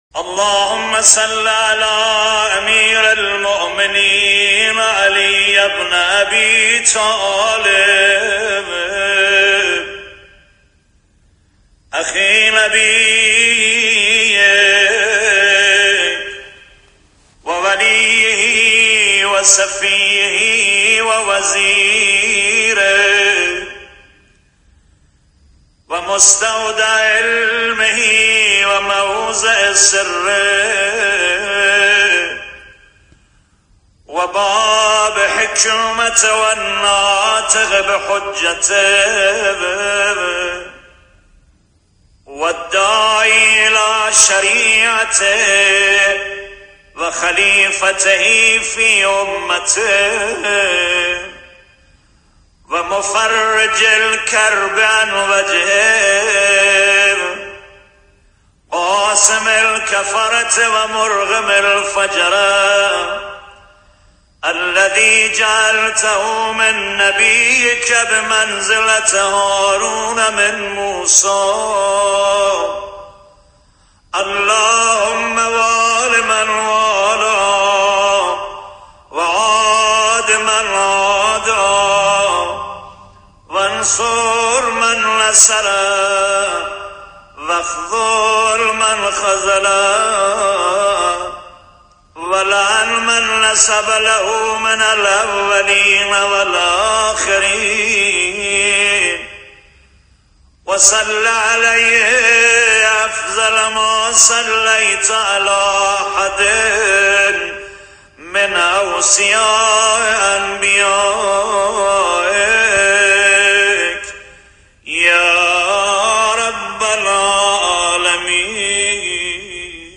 سخنرانی صوتی